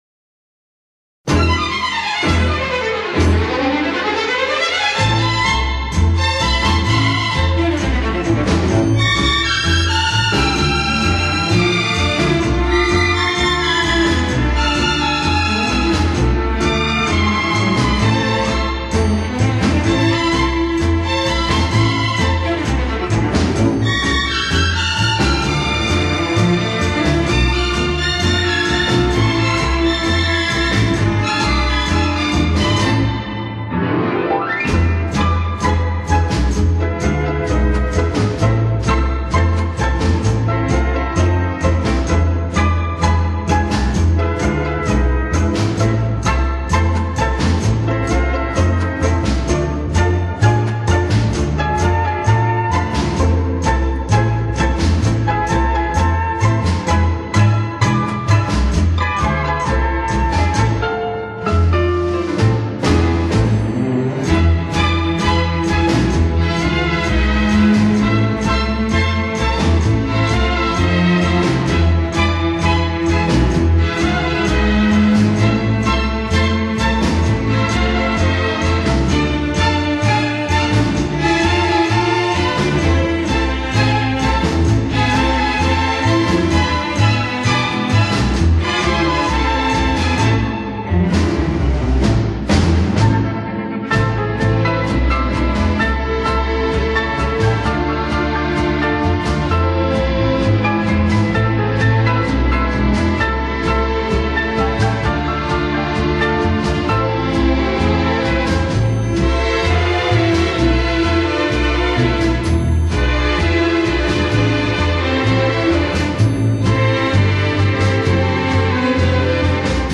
Genre: Instrumental, latin, orchestra